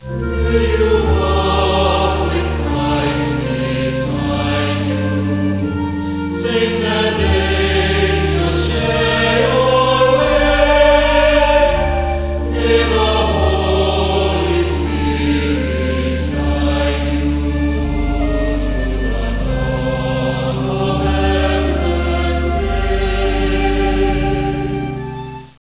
liturgical compositions
traditional, choral, contemporary ballads and hymns.